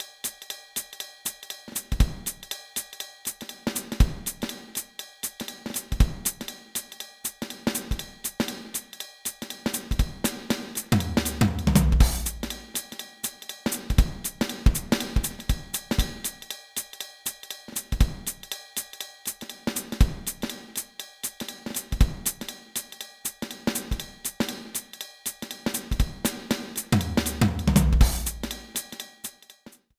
The intro theme